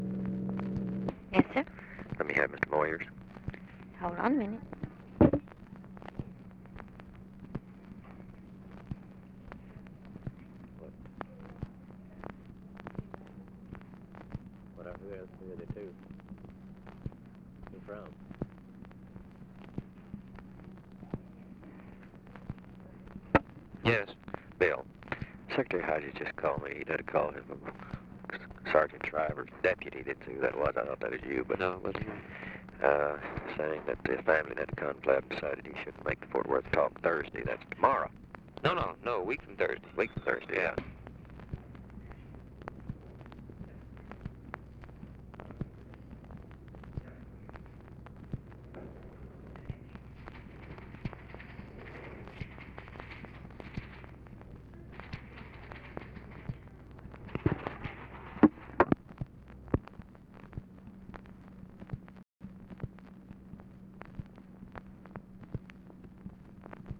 Conversation with BILL MOYERS, December 04, 1963
Secret White House Tapes